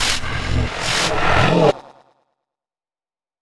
Techno / Voice / VOICEFX198_TEKNO_140_X_SC2(R).wav
1 channel